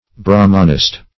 Search Result for " brahmanist" : The Collaborative International Dictionary of English v.0.48: Brahmanist \Brah"man*ist\, Brahminist \Brah"min*ist\, n. An adherent of the religion of the Brahmans.
brahmanist.mp3